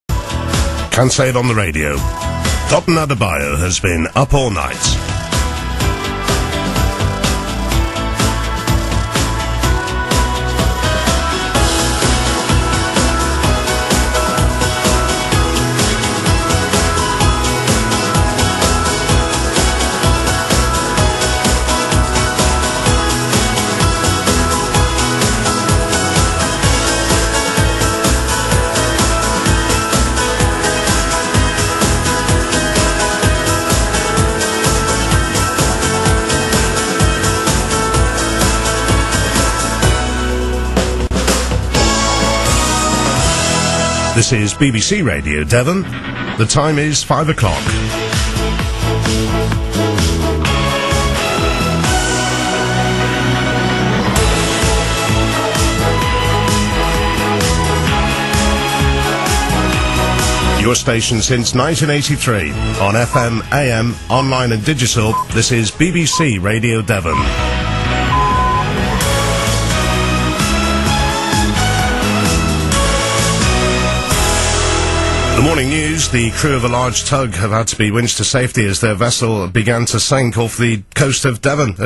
Quite like the opening bed :)